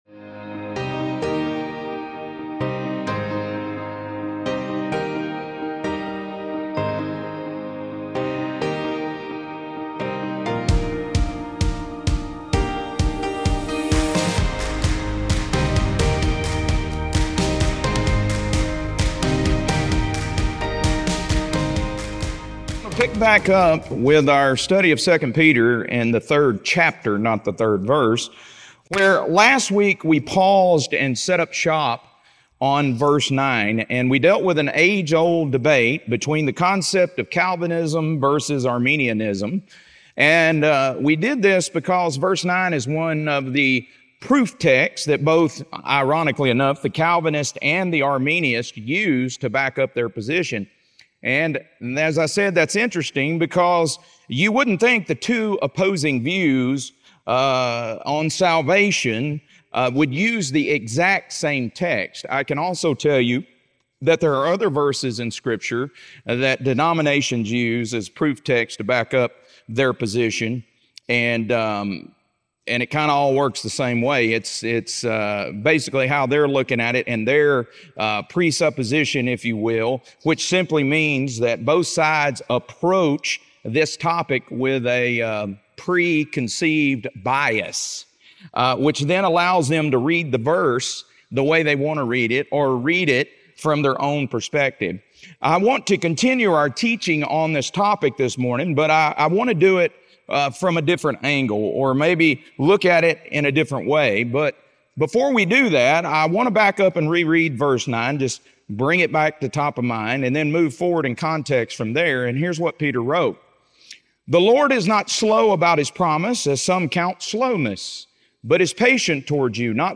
2 Peter - Lesson 3C | Verse By Verse Ministry International